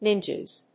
/ˈnɪndʒʌz(米国英語)/